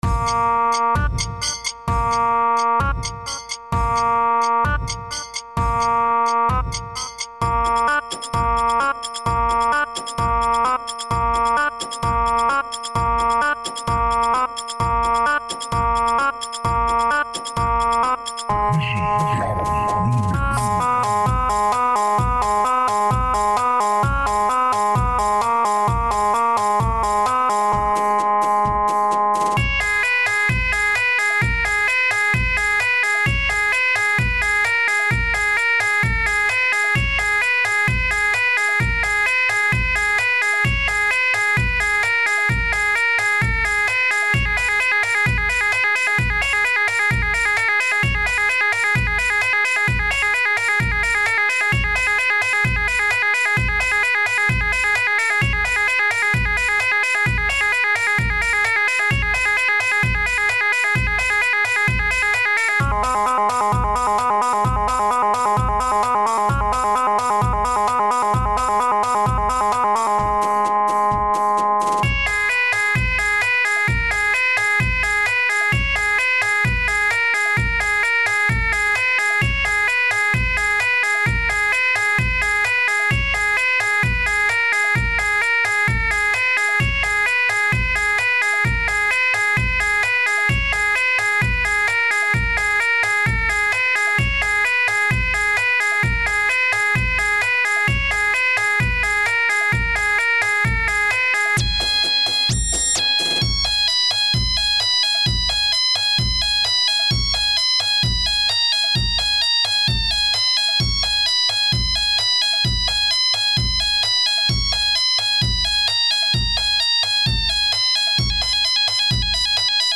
خليجى ومزمار